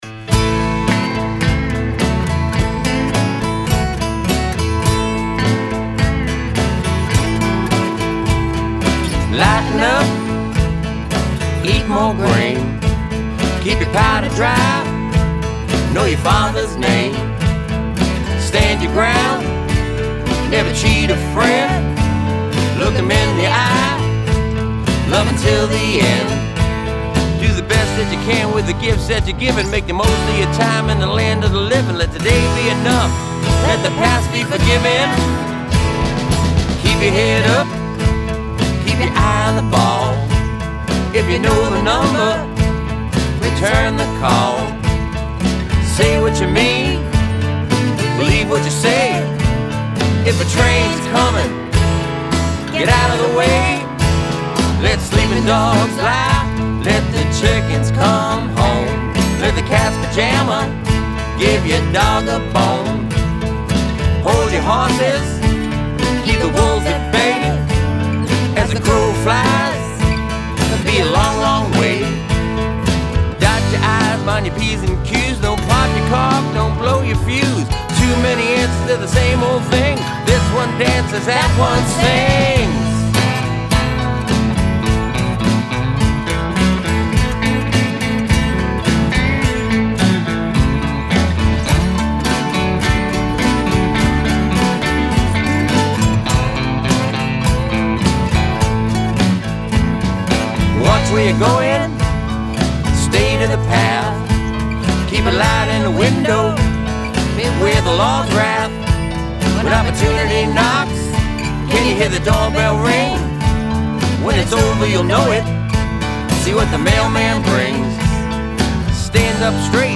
Guitars and Vocals
Drums
Keyboards